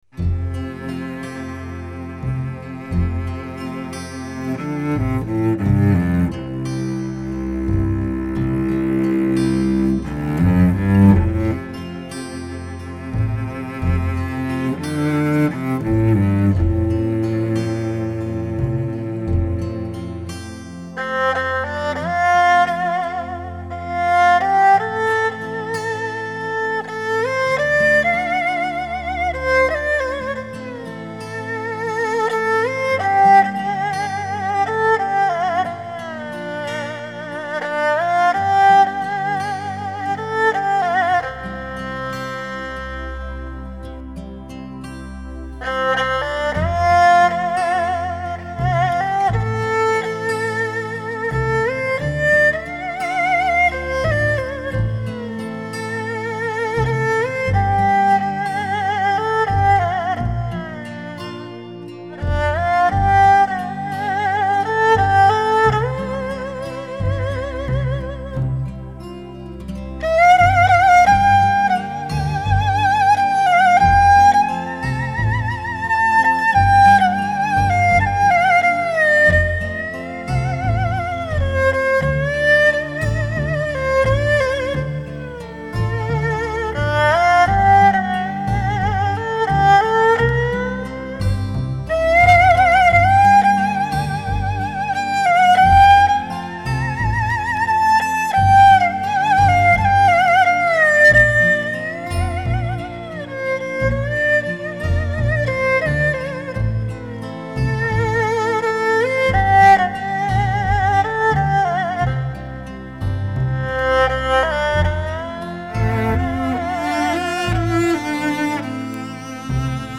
新颖动感柔美的东方气息，令你陶醉于一种忘情的梦幻之境。